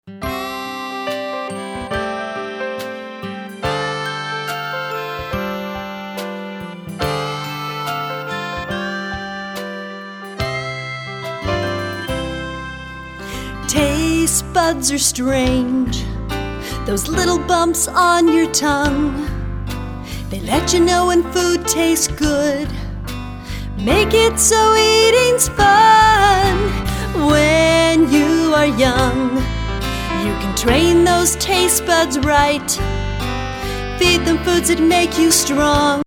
MP3 Demo